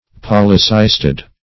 Search Result for " polycystid" : The Collaborative International Dictionary of English v.0.48: Polycystid \Pol`y*cys"tid\, n. (Zool.)